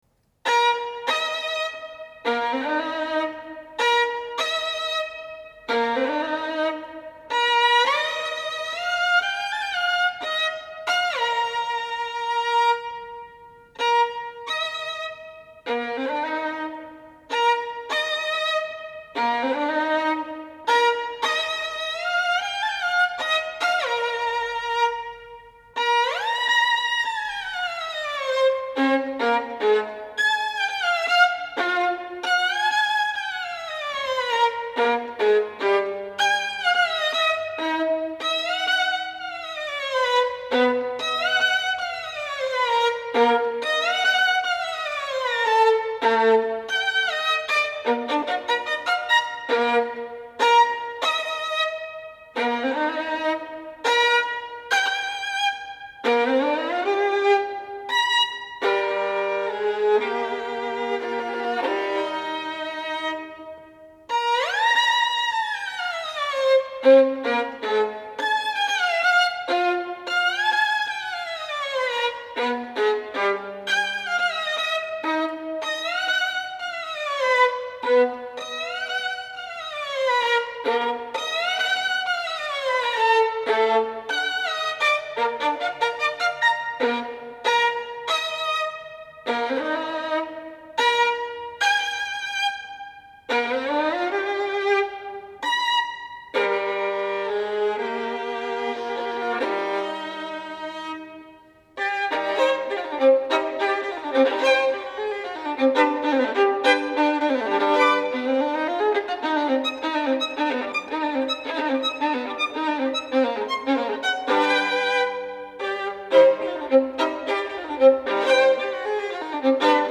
для скрипки соло